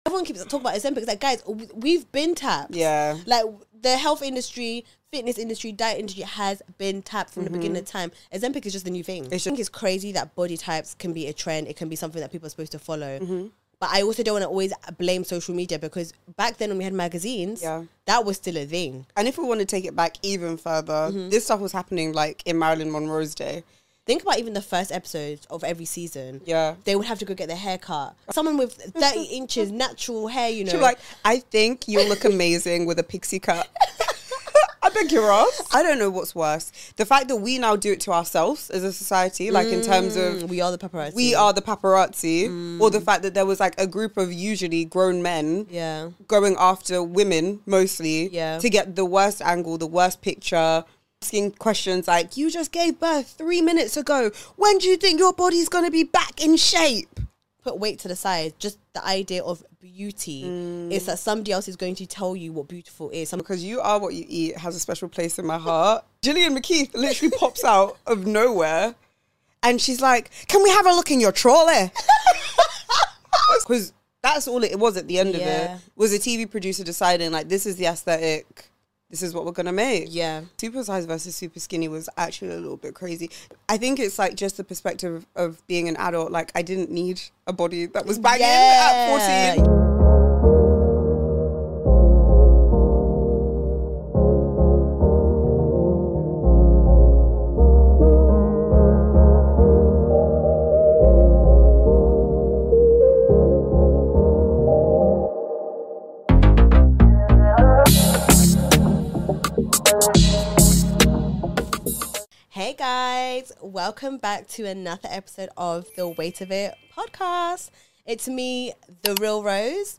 Join me as I share some weighty reflections from my 20's, what weight i'm leaving behind and what i'm looking forward to in my 30's. As always, be prepared to hear a very REAL and RAW conversation.